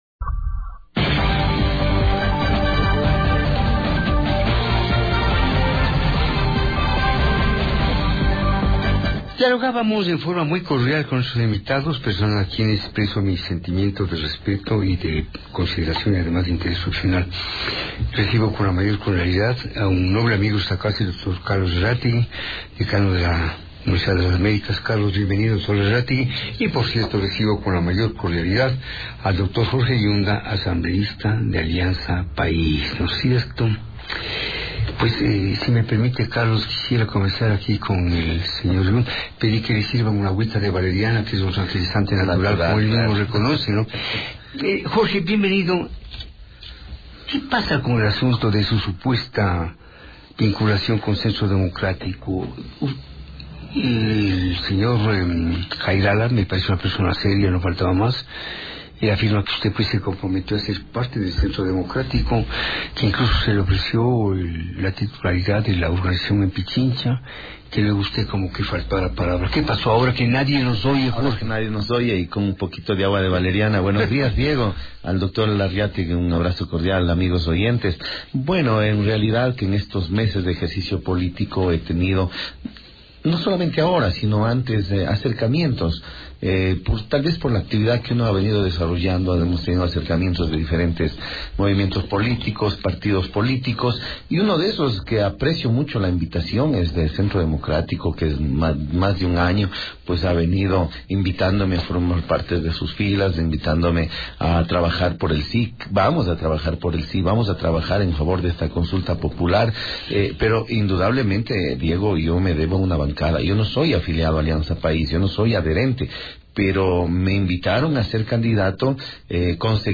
Durante una entrevista en el programa Buenos Días con Diego Oquendo